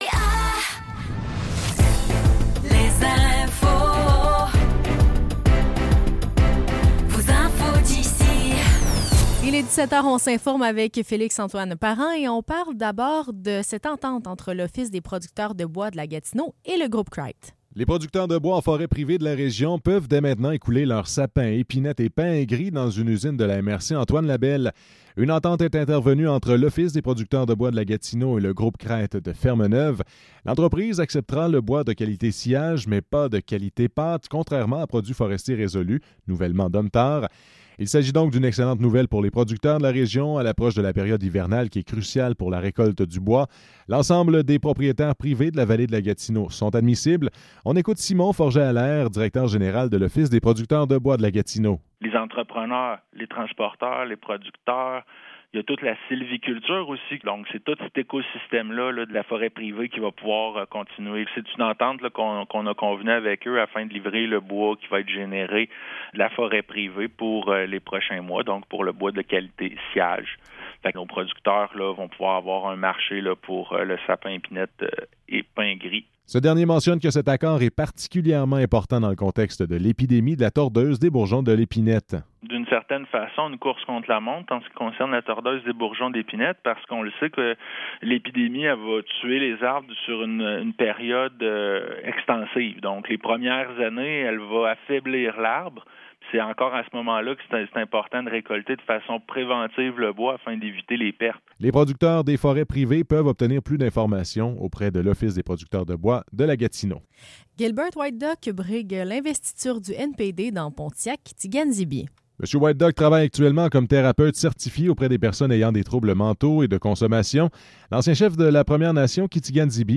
Nouvelles locales - 11 novembre 2024 - 17 h